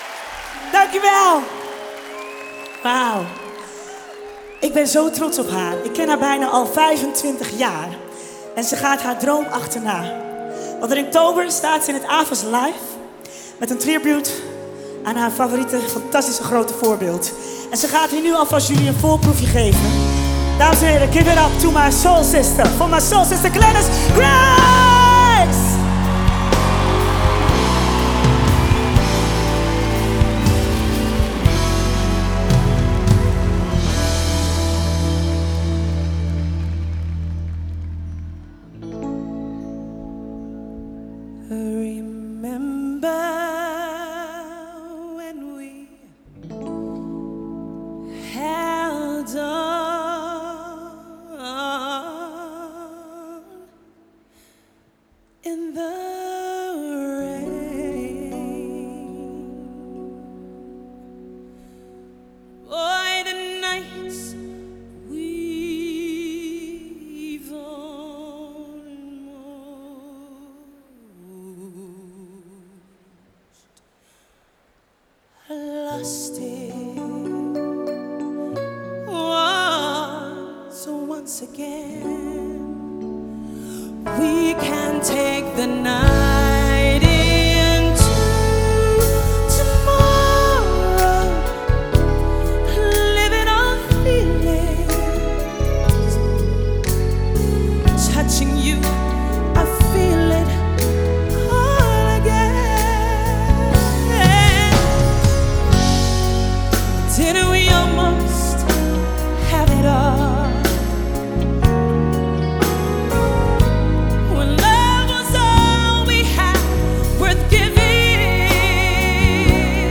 Genre: Soul.